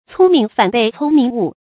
聪明反被聪明误 cōng míng fǎn bèi cōng míng wù 成语解释 自以为聪明反而被聪明耽误或妨害了。